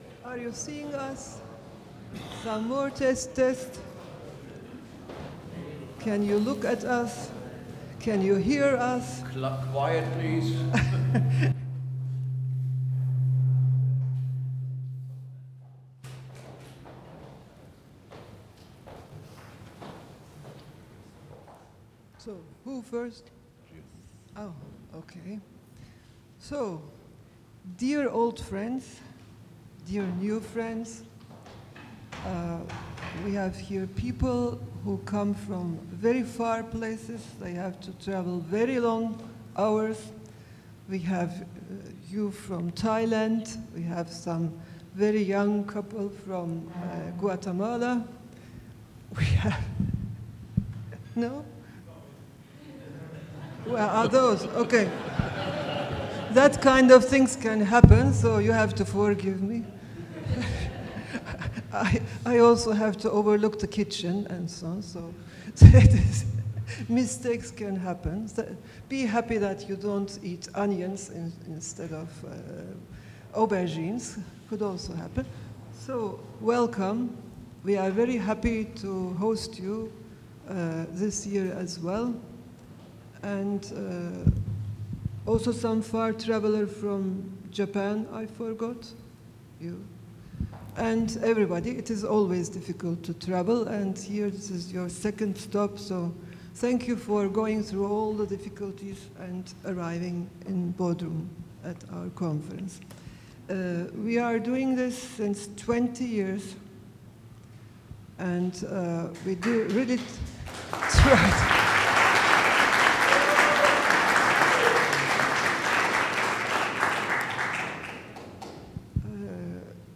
These introductory remarks are from the recently-concluded 19th annual PFS 2025 Annual Meeting (Sep. 18–23, 2025, Bodrum, Turkey).
Brief audio/video test before the session begins. Music plays as the audience settles.
Audience laughter and applause.